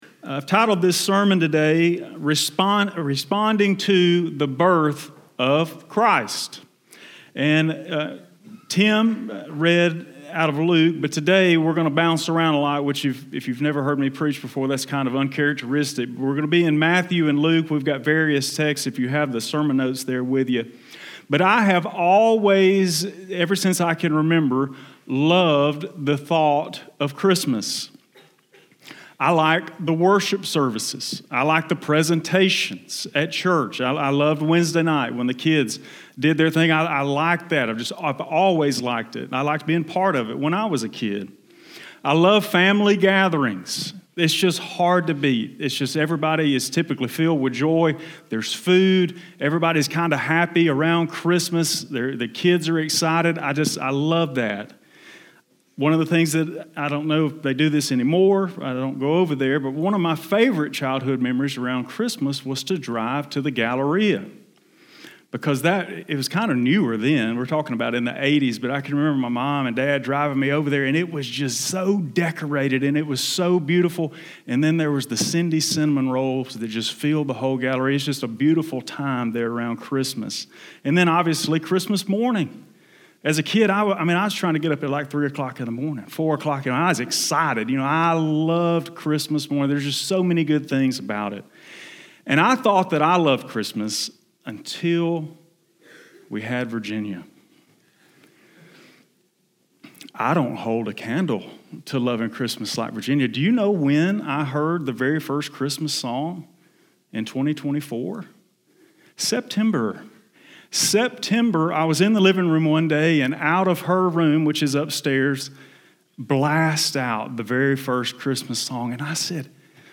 What response to Christmas brings God the most glory? Today, we look at 10 responses to the birth of Jesus in hopes to understand the best way to respond. We hope you enjoy the service and gain new meaning from the Word.